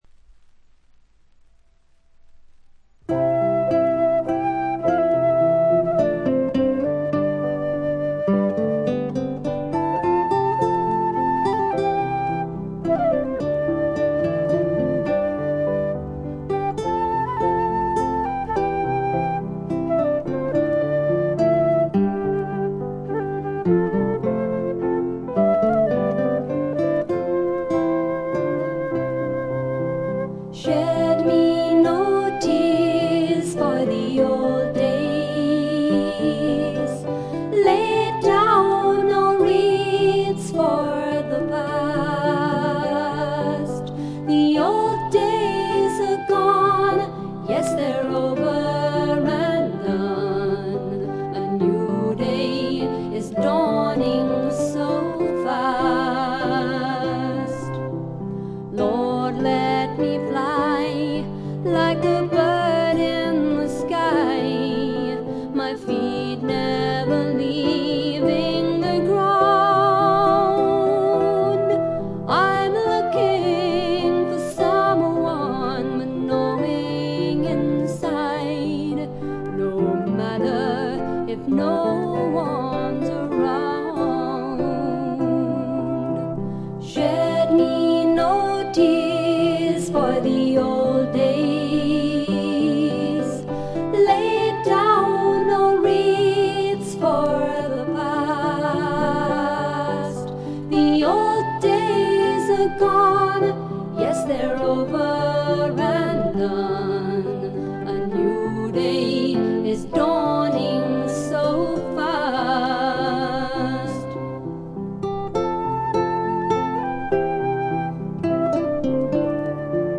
英国の男女フォーク・デュオ
しみじみとした情感が沁みてくる歌が多いです。
Track 3 recorded at Sound Conception, Bristol;